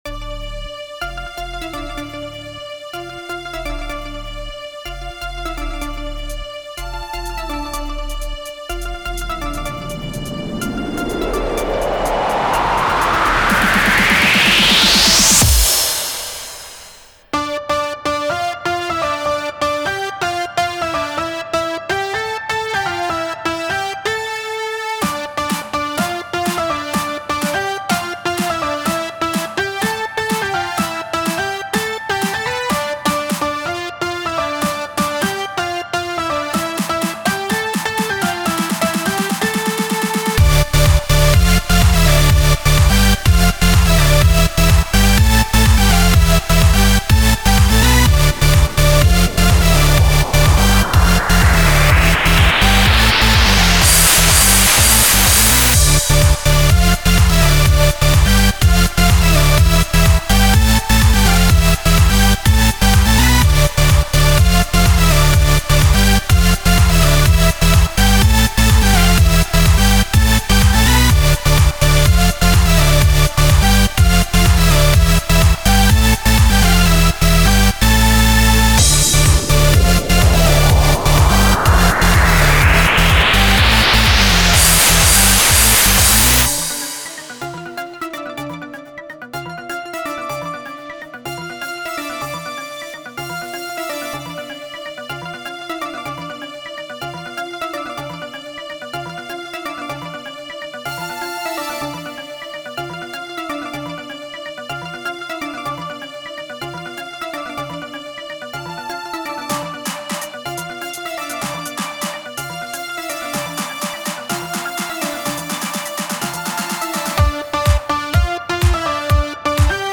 I think this is one of my better tracks, and has an energetic vibe to it.